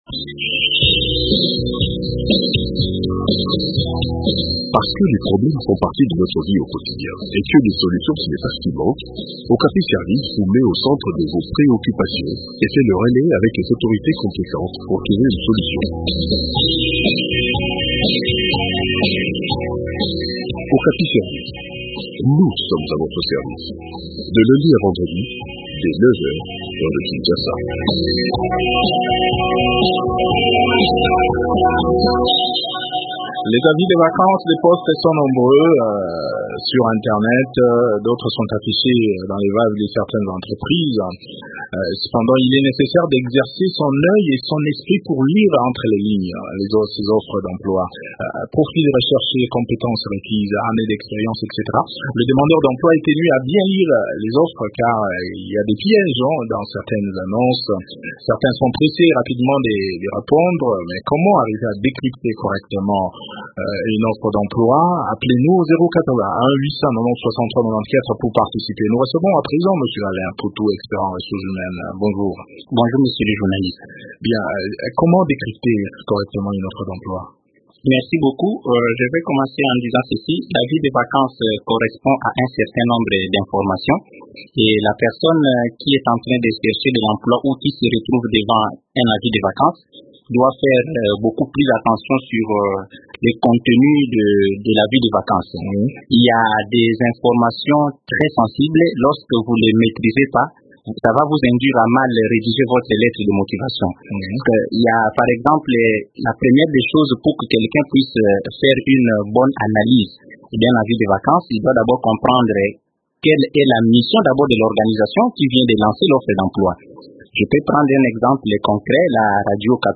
expert en ressources humaines